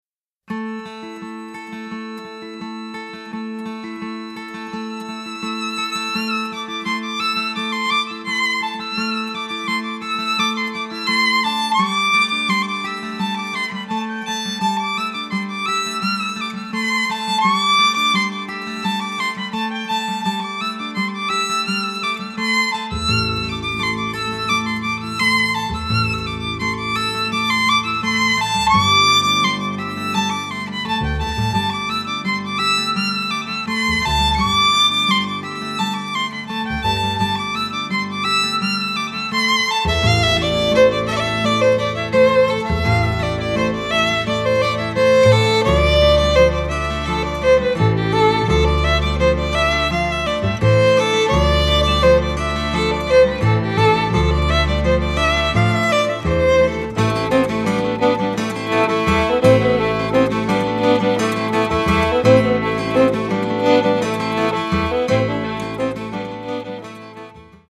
These two musicians connected instantly on both musical and personal levels and have since fuelled each other’s creativity in a non-stop musical exploration.